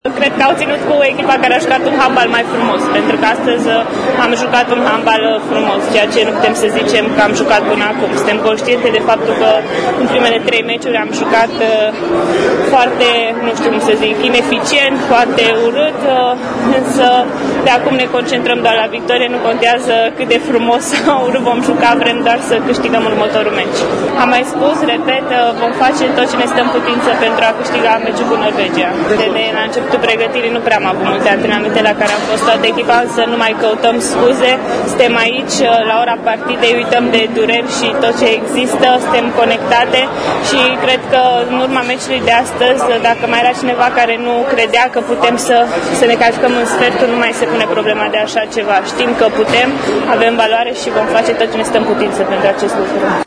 Interviu audio cu Eliza Buceschi